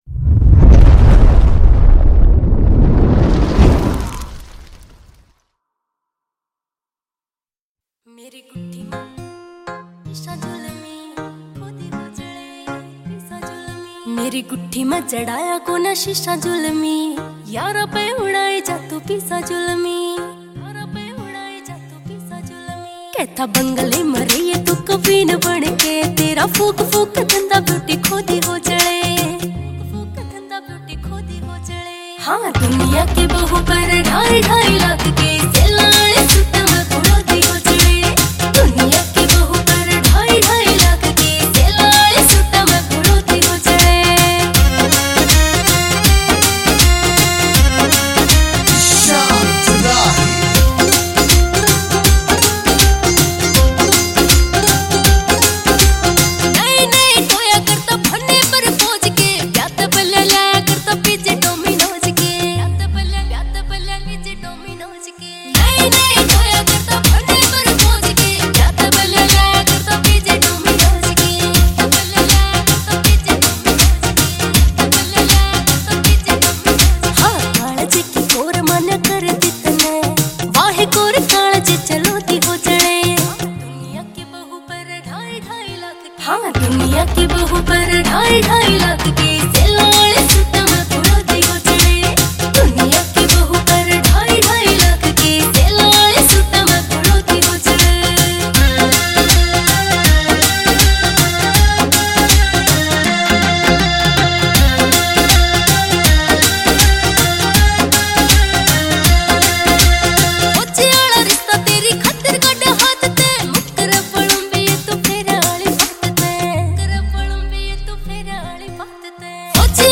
Haryanvi Single Tracks